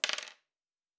pgs/Assets/Audio/Fantasy Interface Sounds/Dice Single 3.wav at master
Dice Single 3.wav